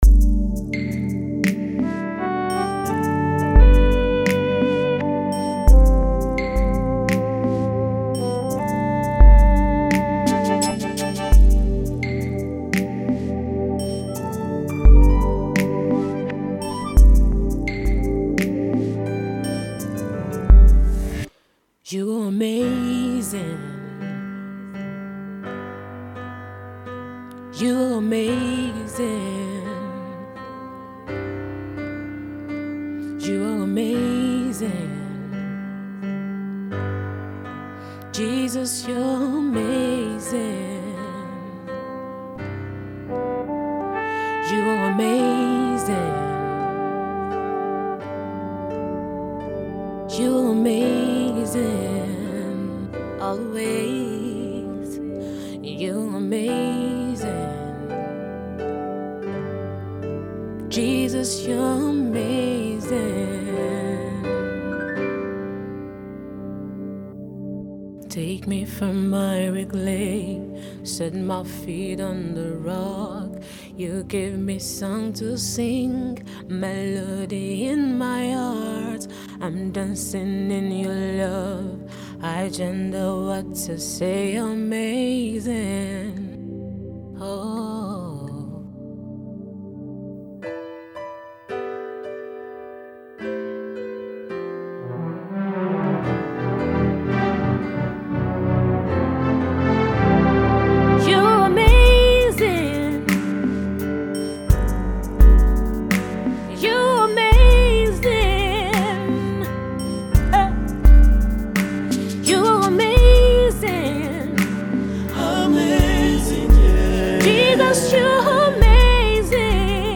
Gospel female music minister